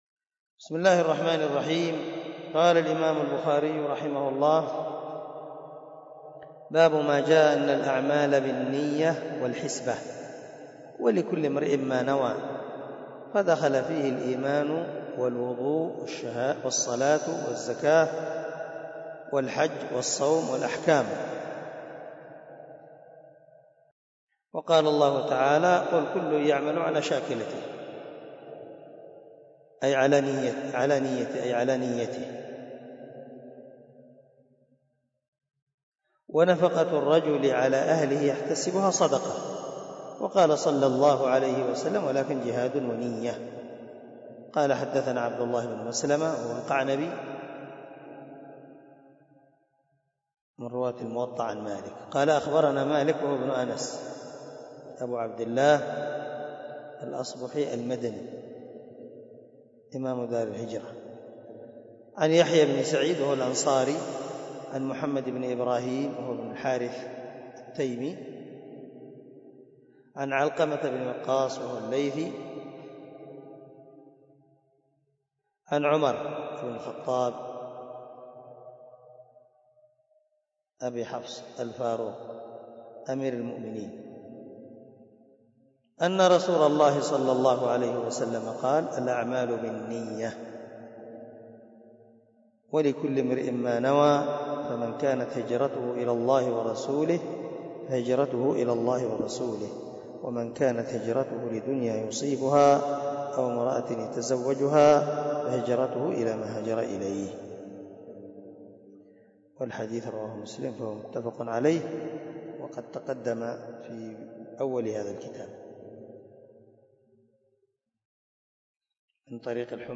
053الدرس 43 من شرح كتاب الإيمان حديث رقم ( 54 - 56 ) من صحيح البخاري
دار الحديث- المَحاوِلة- الصبيحة.